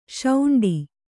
♪ śauṇḍi